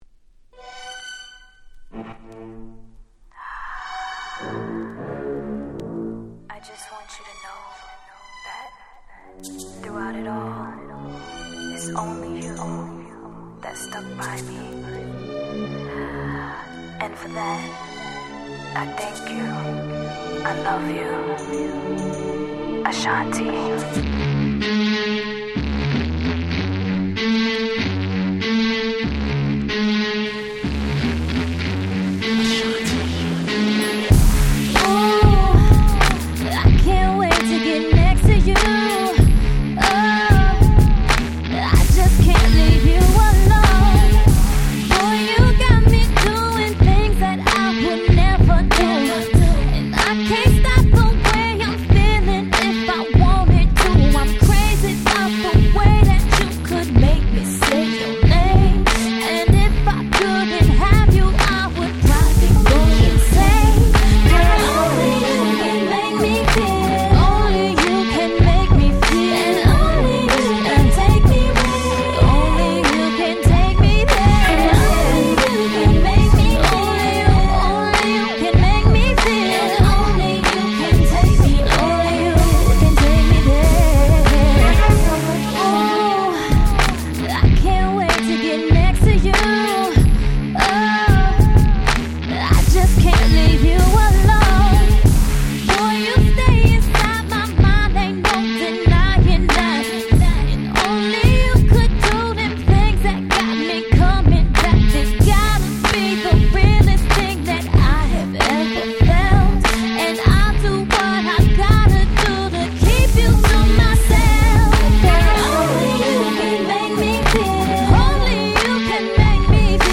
※試聴ファイルは別の盤から録音してございます。
04' Super Hit R&B !!
アルバム全体的に前作、前々作と比べて落ち着いた大人な内容だった印象、こちらのカットも壮大に歌い上げる素晴らしい1曲です。